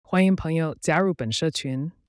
TTS
生成的音频文件：